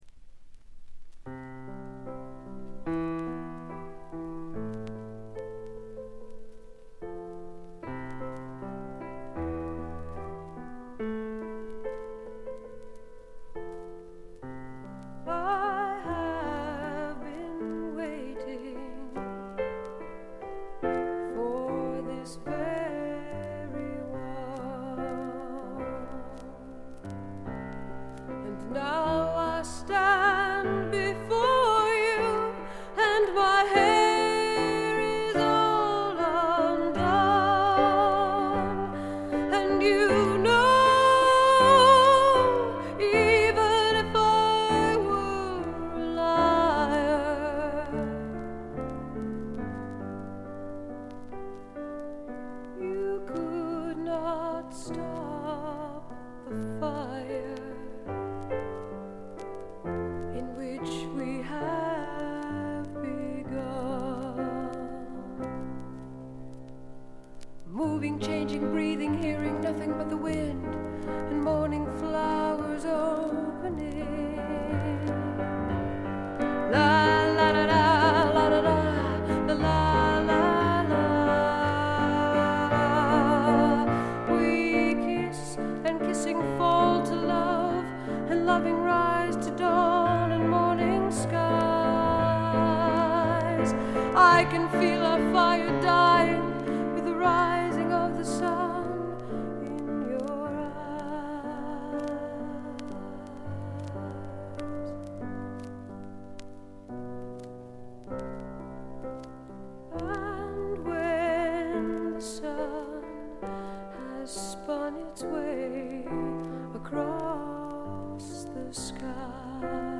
部分試聴ですが軽微なバックグラウンドノイズ、チリプチ程度。
フェミニスト系の女性シンガソングライター
試聴曲は現品からの取り込み音源です。